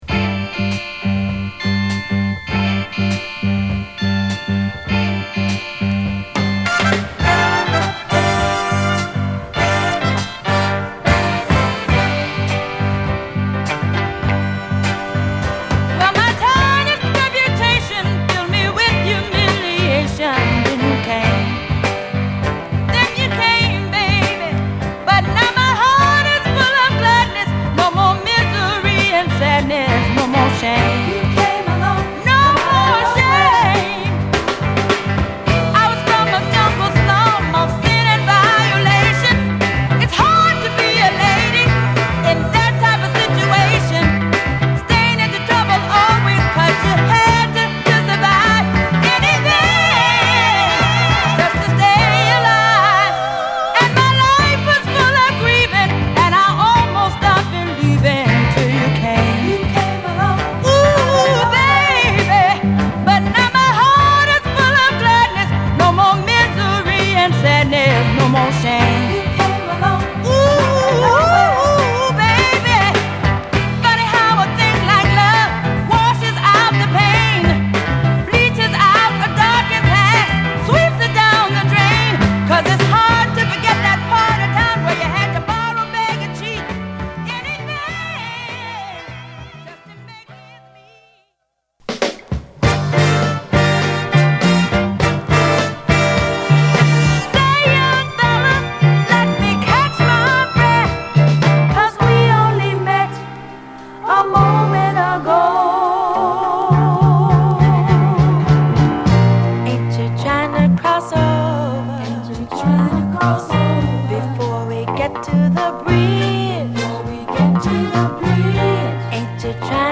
カッコ良いイントロから、打ったドラムにギター/ホーンを絡めジワジワと盛り上がりをみせるファンキーレディ・ソウル